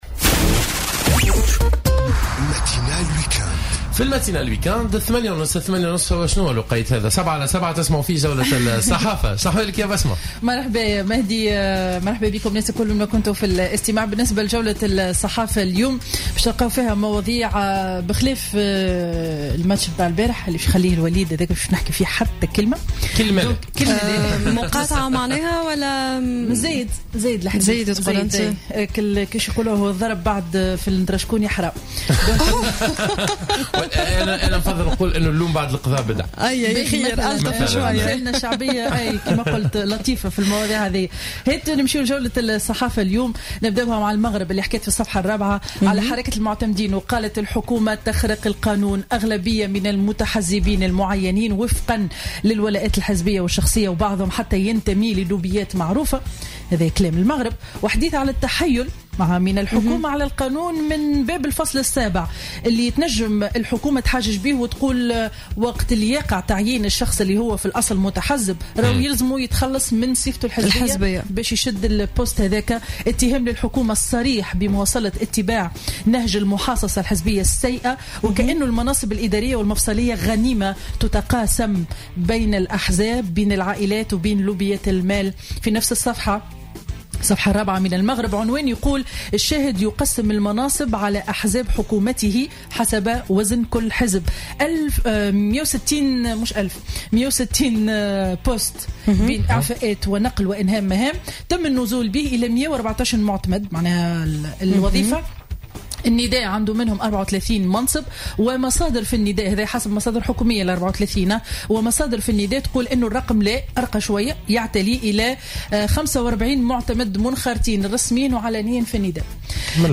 Revue de presse du Dimanche 29 Janvier 2017